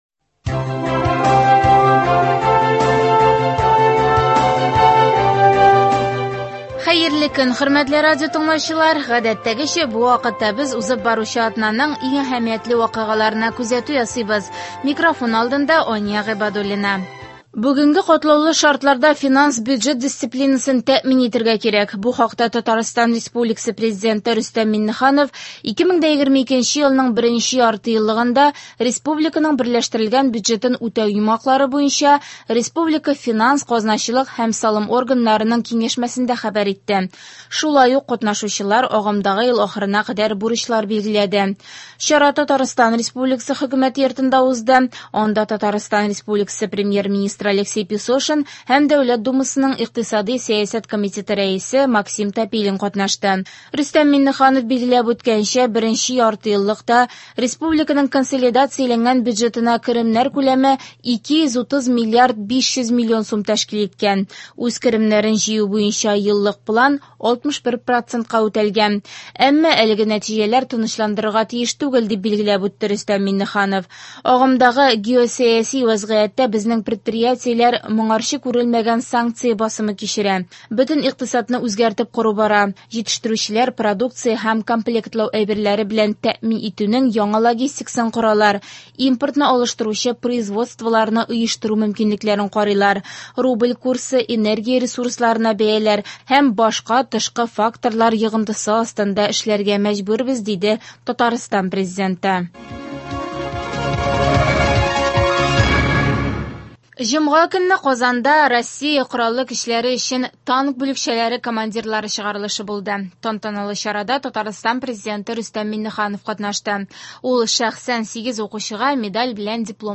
Атналык күзәтү. Бүгенге катлаулы шартларда финанс-бюджет дисциплинасын тәэмин итәргә кирәк.